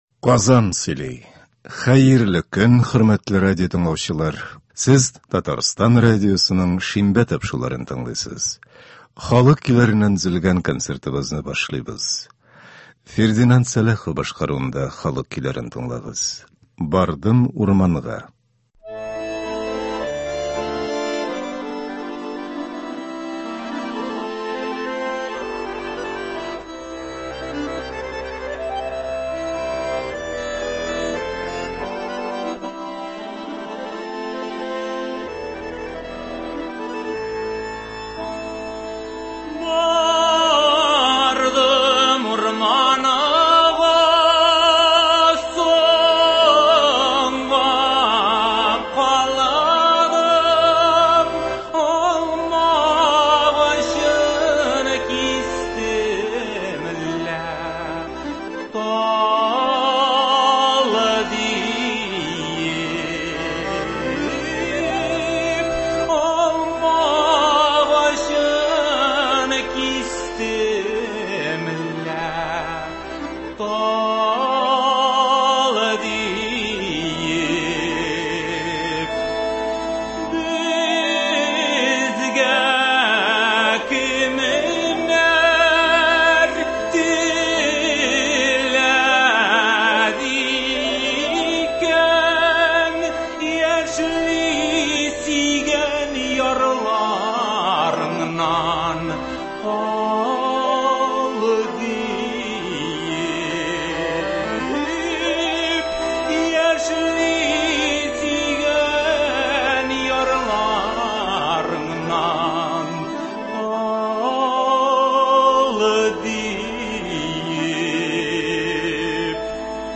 Татар халык көйләре. 29 февраль.
Бүген без сезнең игътибарга радио фондында сакланган җырлардан төзелгән концерт тыңларга тәкъдим итәбез.